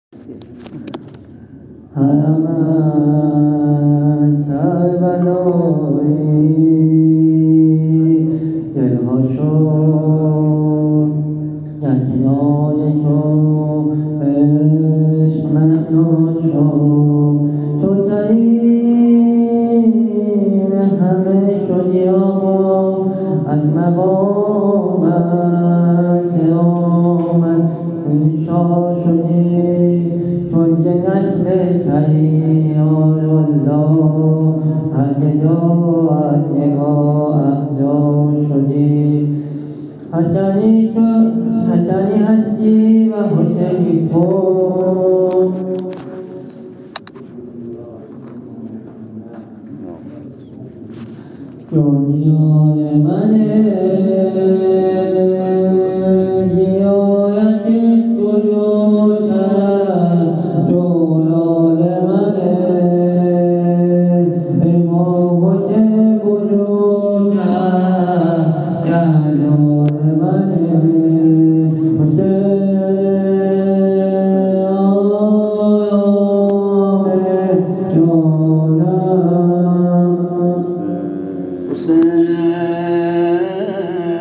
مدح عبدالعظیم حسنی مسجد حاج حسن خرقانی
مداح اهل بیت